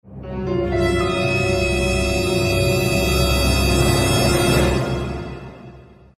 Play, download and share Suspenss original sound button!!!!
suspenss.mp3